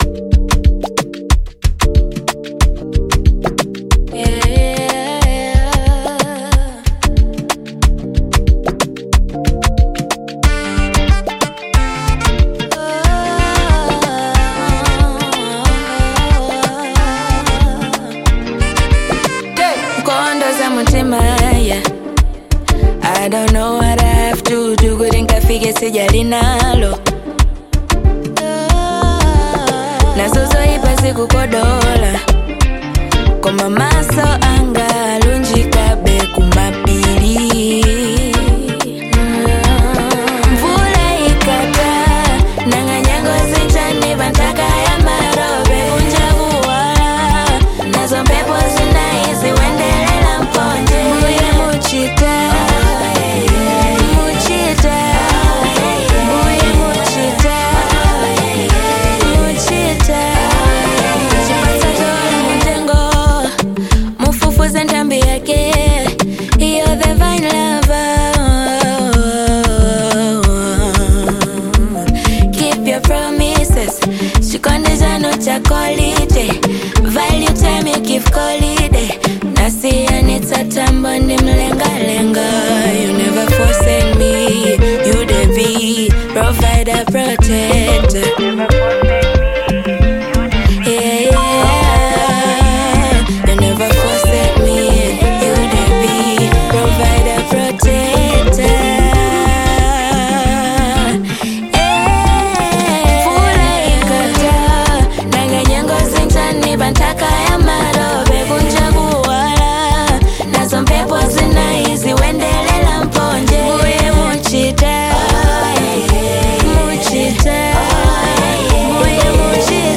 Genre : Afro Pop Fusion
Blending smooth Afro rhythms with catchy pop melodies
With its infectious beat
signature soulful delivery